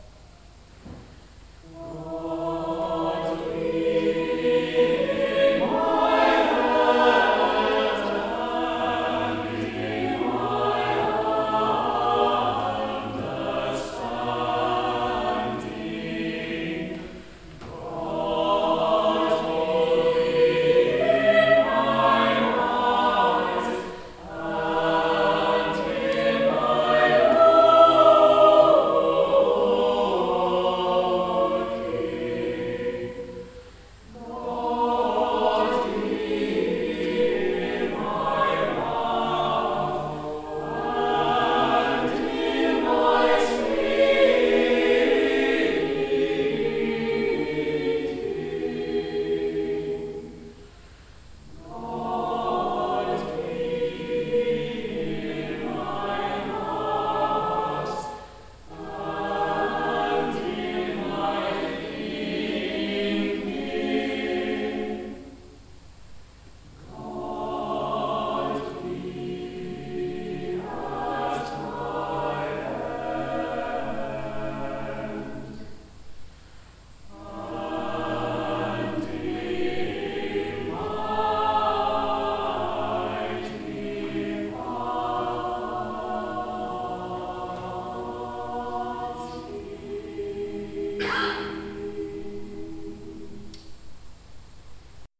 Recorded at Choral Evensong, 10th Feb 2019. Performed by Huddersfield Parish Church Choir.